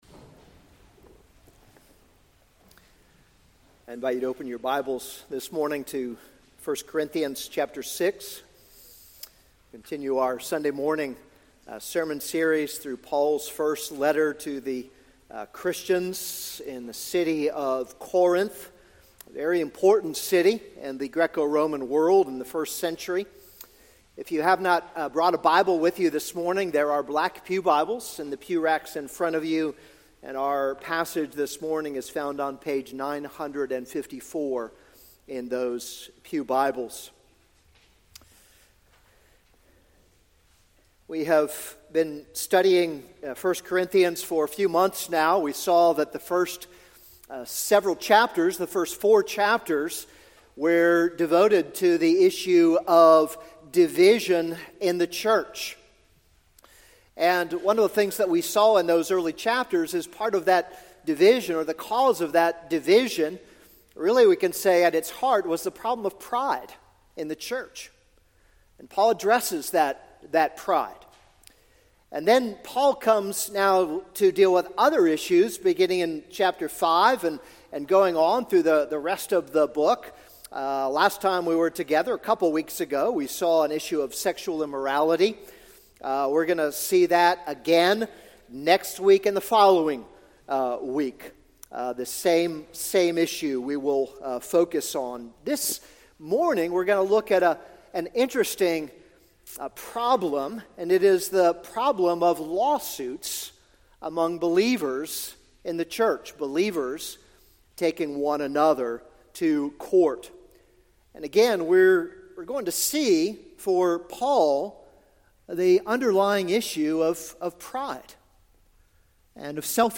This is a sermon on 1 Corinthians 6:1-11.